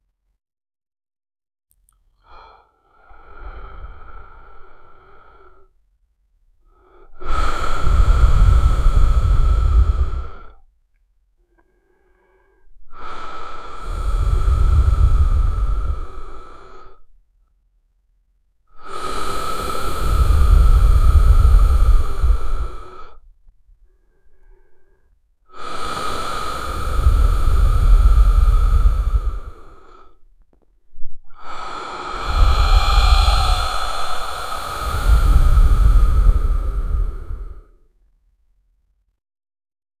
Royalty-free breathing sound effects
handsome young man breathing normally WITH MOUTH CLOSE realistically